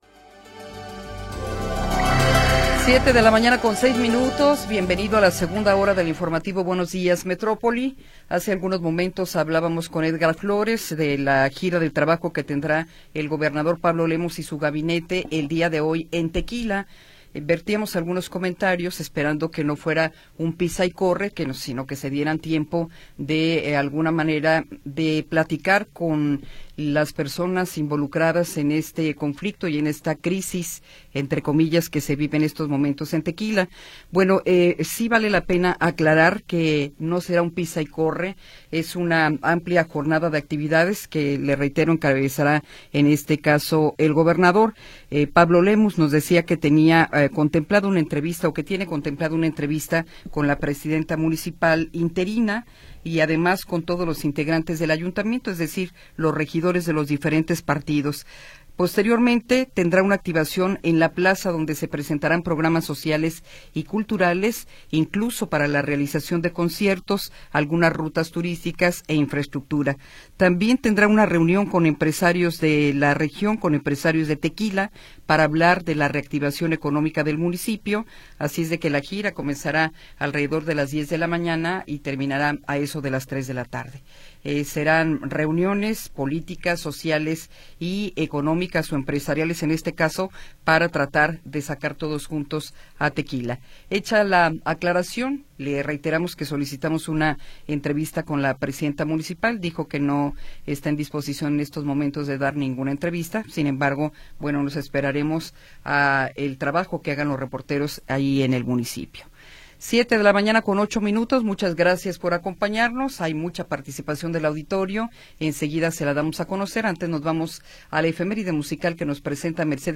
Segunda hora del programa transmitido el 10 de Febrero de 2026.